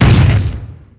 mega_bouncehard2.wav